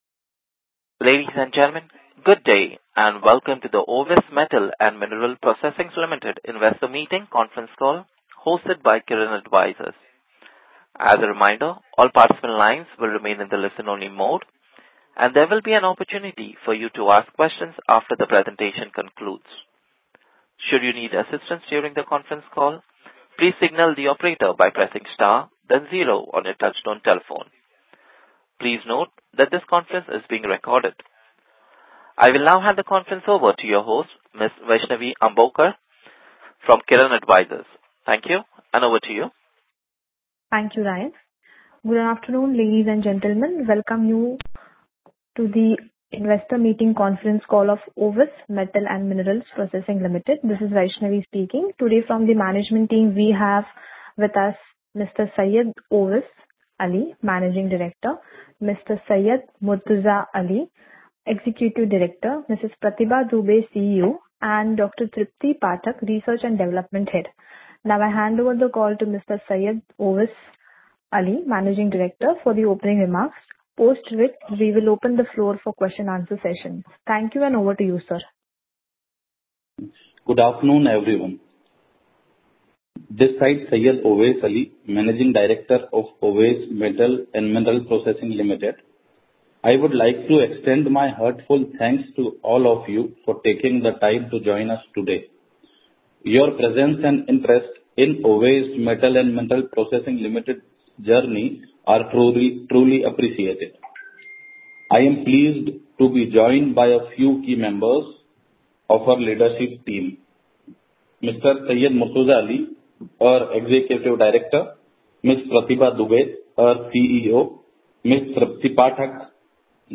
Analysts/Institutional Investor Meet/Con. Call Updates
Owais Metal and Mineral Processing Limited- Investor Meeting  Concall Audio Recording.mp3